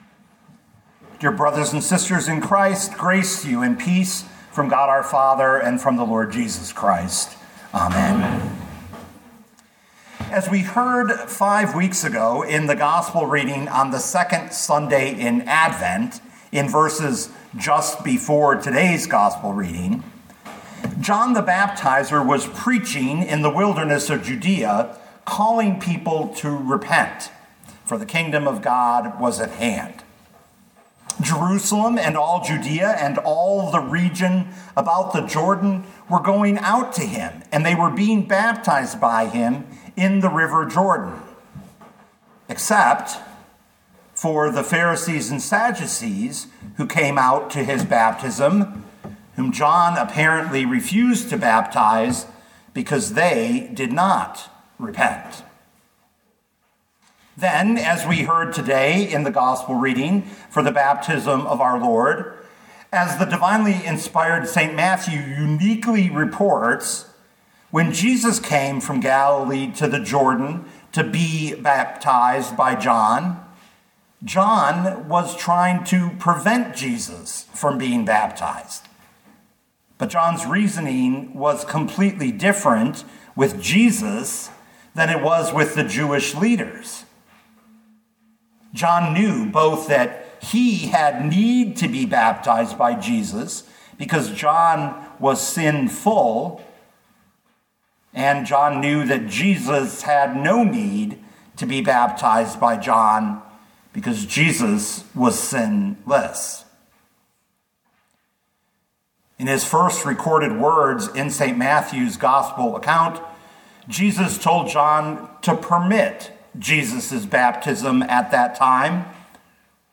2026 Matthew 3:13-17 Listen to the sermon with the player below, or, download the audio.